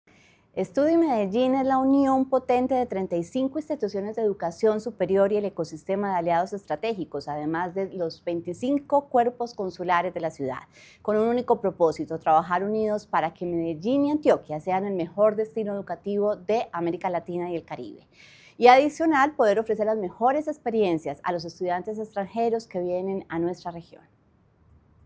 Declaraciones-secretaria-de-Turismo-y-Entretenimiento-Ana-Maria-Lopez.mp3